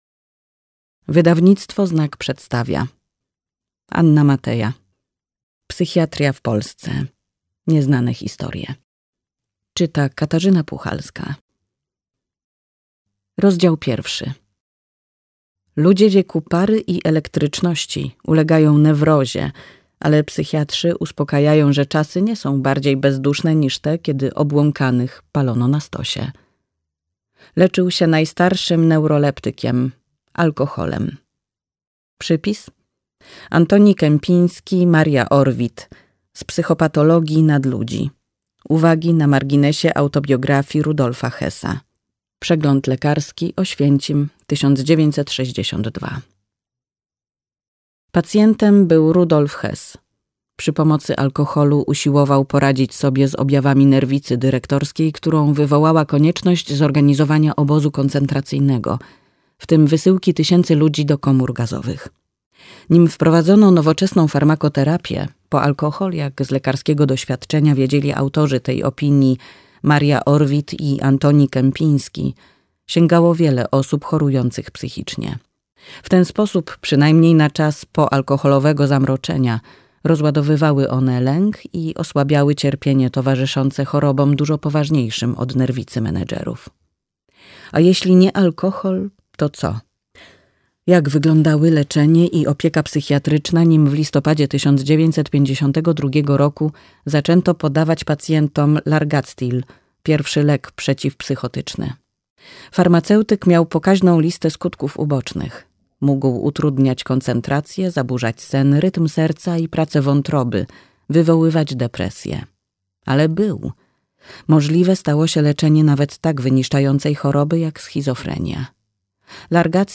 Psychiatria w Polsce. Nieznane historie - Anna Mateja - audiobook